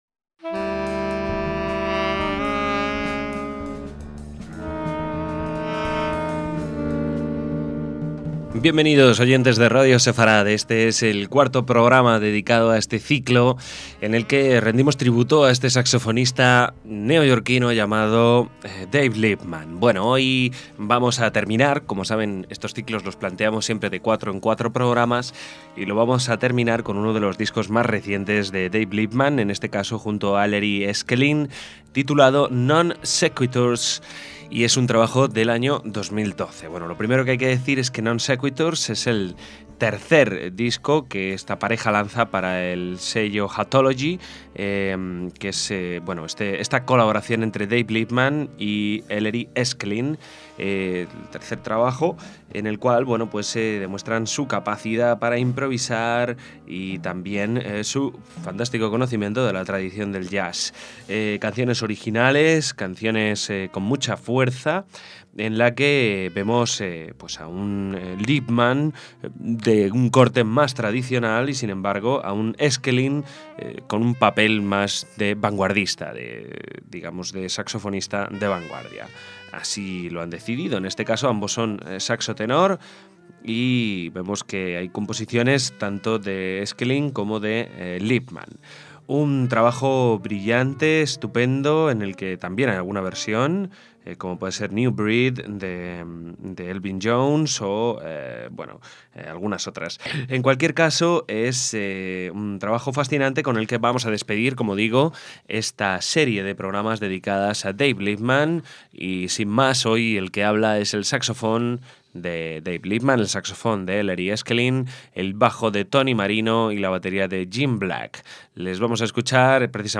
otro saxofonista tenor